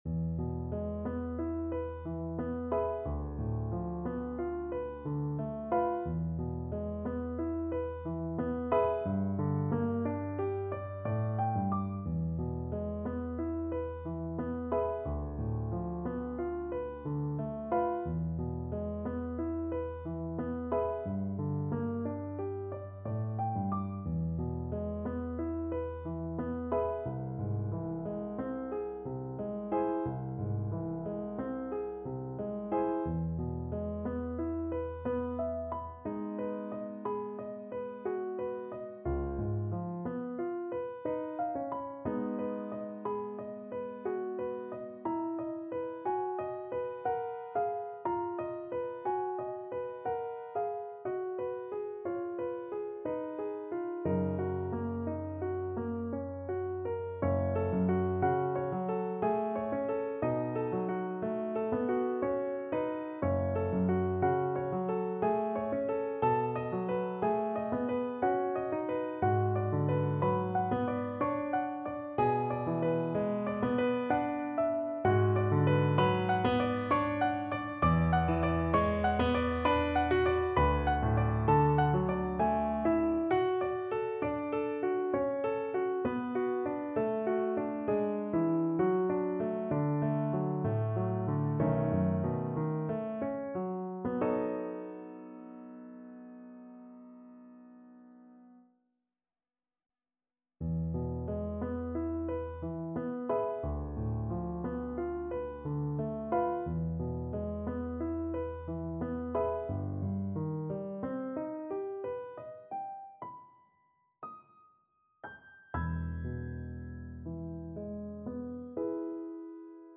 3/4 (View more 3/4 Music)
Andante ma non troppo =60